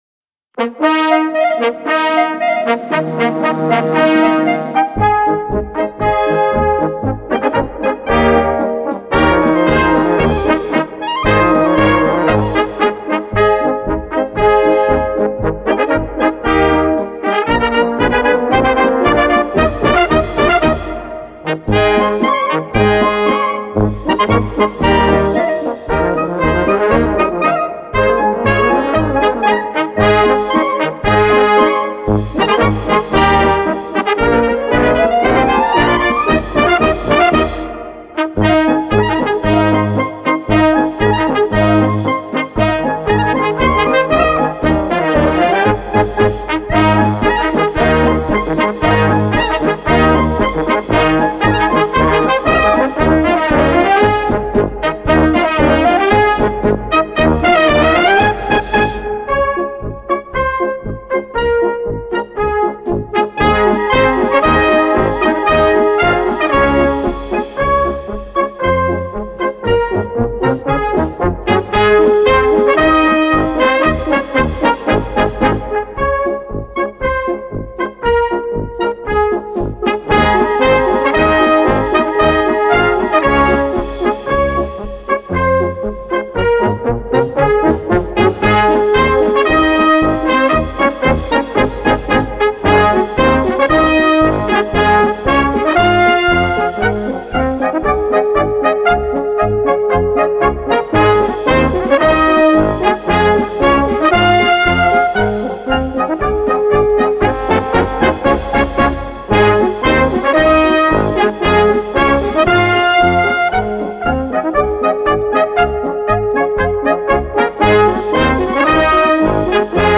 Category Concert/wind/brass band
Subcategory Polka
Instrumentation klBlm (small wind band)